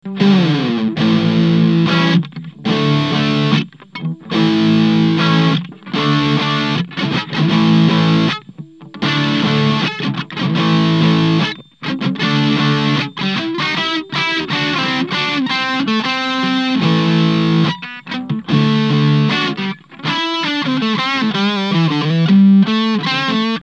humbucker neck
TS9_humb_neck.mp3